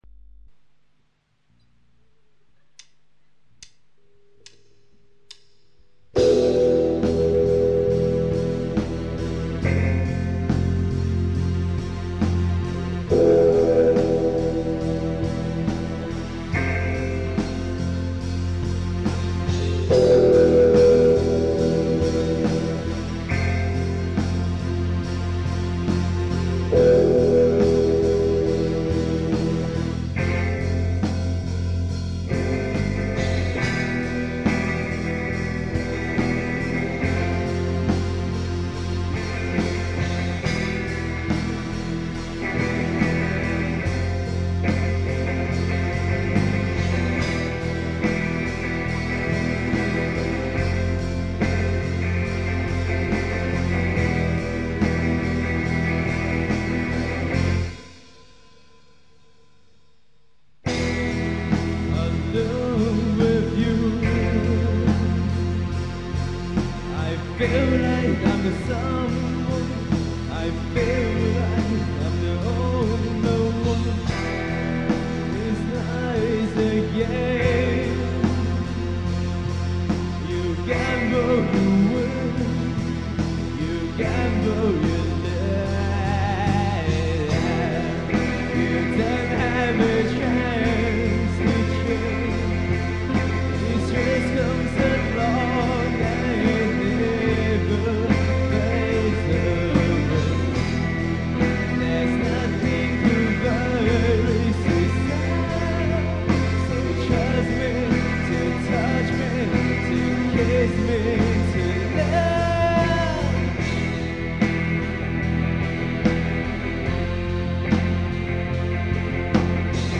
- Groovebox - ·s